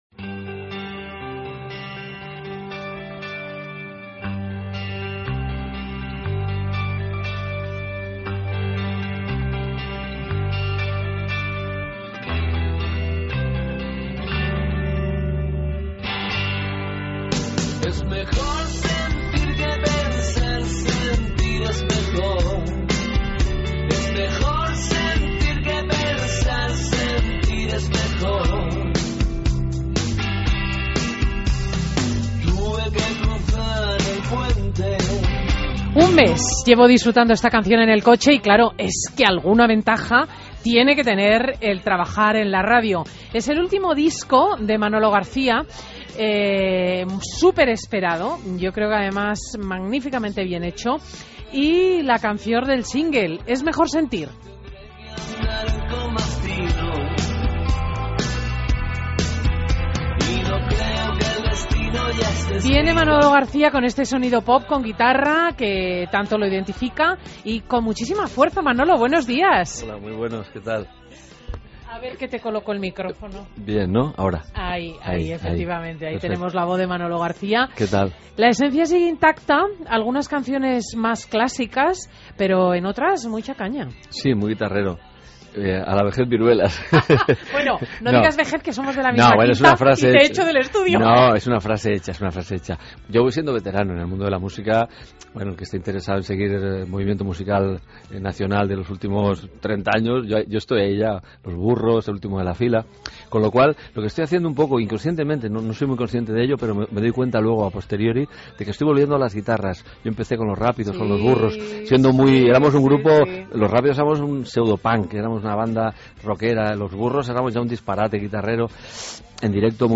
Entrevista a Manolo García en Fin de Semana COPE